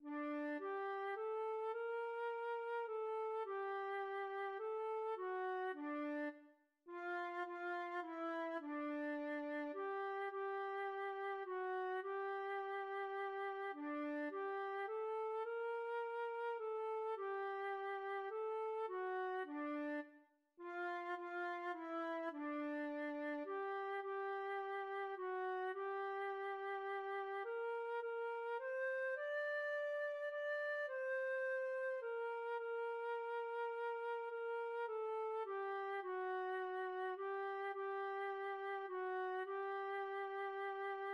Melody in 3/2 time by Georg Neumark 1657